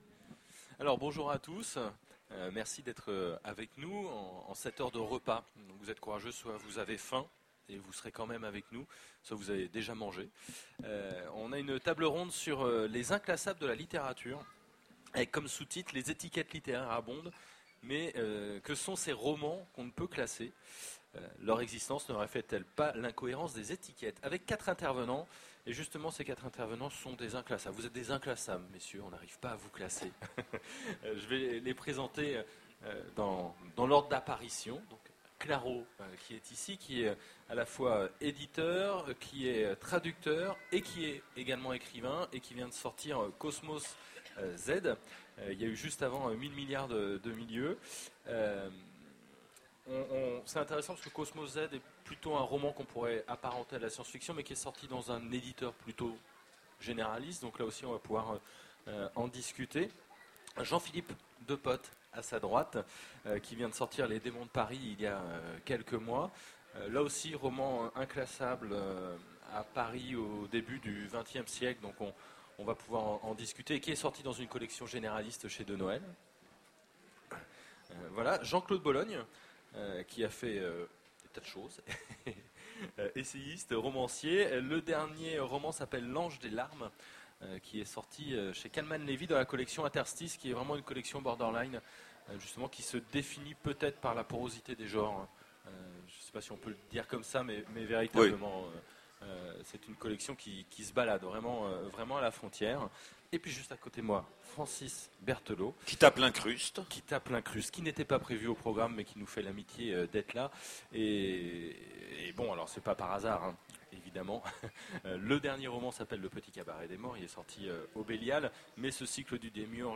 Utopiales 2010 : Conférence Les inclassables en littérature
Voici l'enregistrement de la conférence " Les Inclassables en littérature " aux Utopiales 2010. Les étiquettes littéraires abondent, mais que sont ces romans qu’on ne peut classer ? Leur existence ne reflète-t-elle pas l’incohérence des étiquettes ?